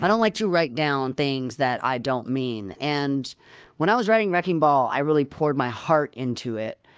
Guess which part is synthesized!